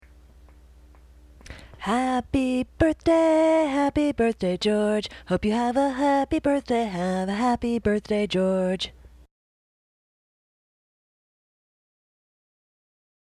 As you can tell from listening to them, I am not a professional singer. But what good is a Happy Birthday replacement if anyone can't just bust it out, with no accompaniment?